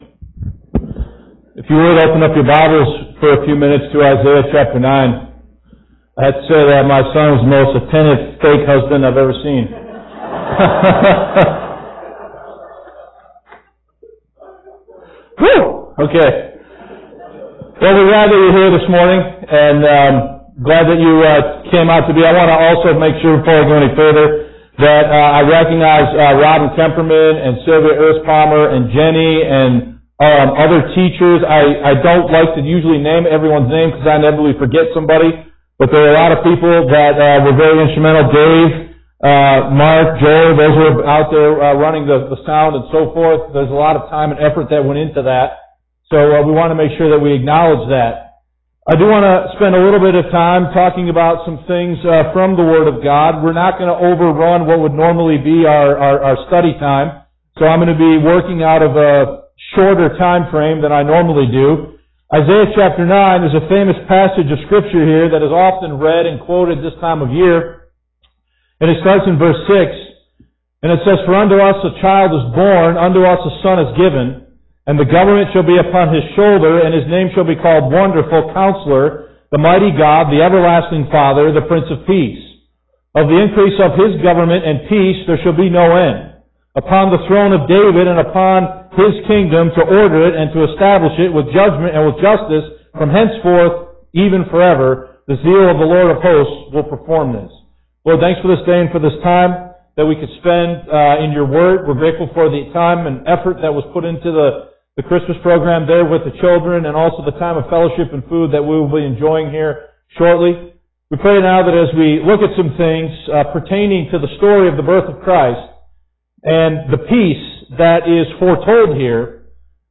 The MP3 audio contains only the message portion of the video.